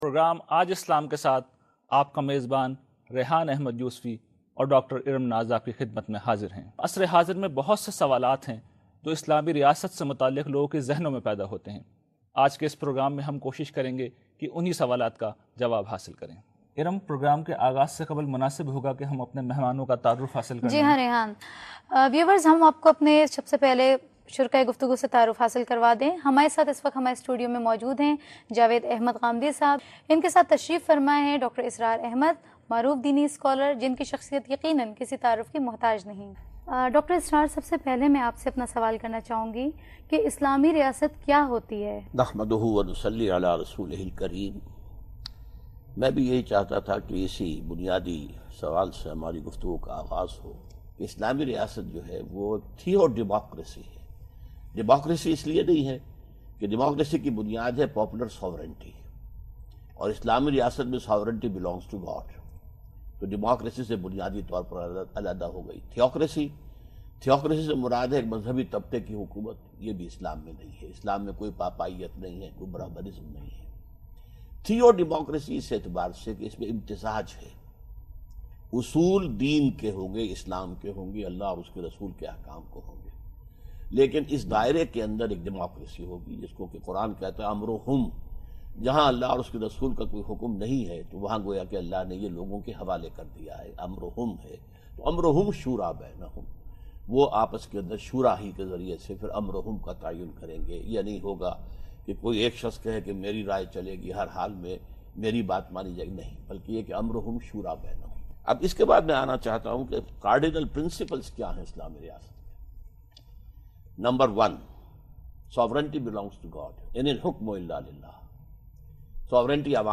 A discussion or debate over political concepts of Islam.